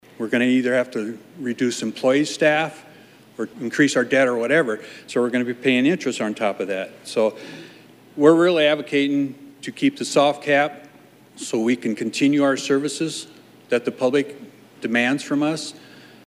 Carroll City Councilman LaVern Dirkx, who also serves on the Iowa League of Cities Executive Board, spoke before an Iowa House Committee on Tuesday as they hosted a public hearing on the Senate’s tax reform proposals.